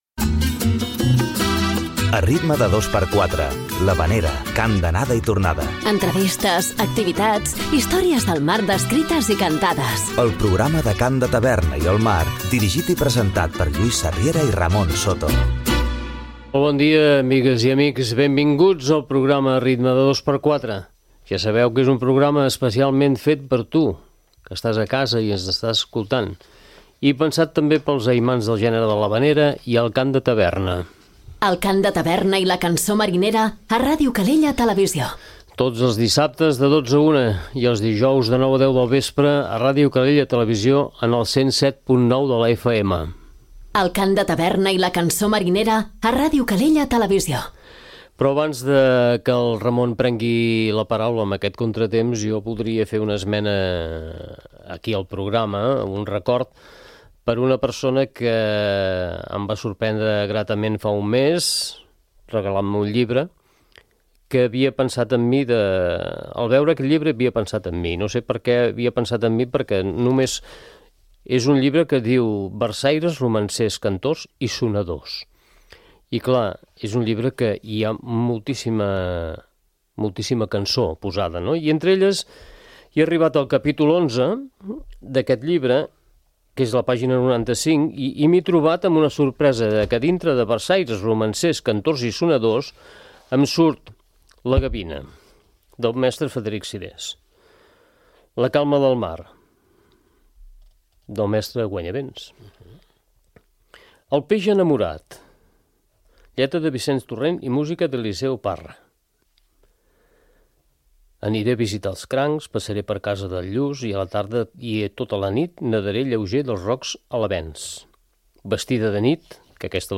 En el programa d'avui parlarem i escoltarem treballs d'autors de ses illes, amb les veus dels diferents grups d’havaneres.